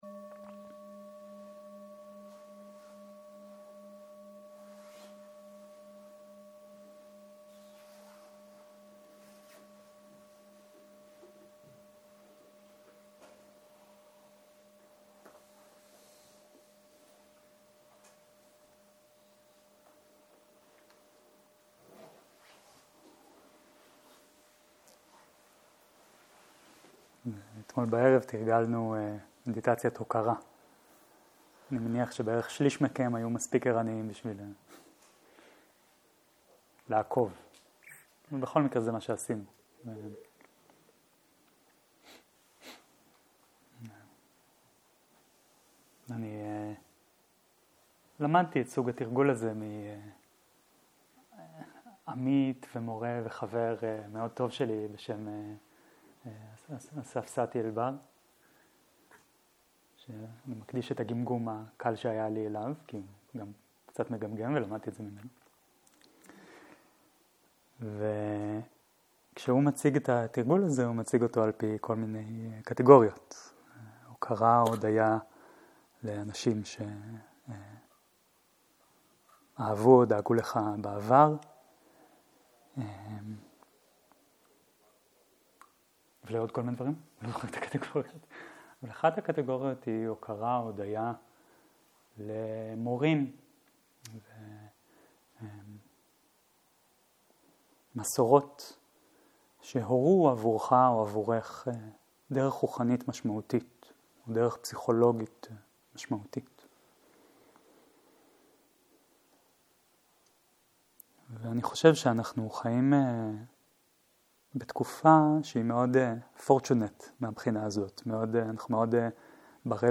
10.02.2023 - יום 3 - ערב - שיחת דהרמה - מיומנות עם מכשולים וקשיים - הקלטה 4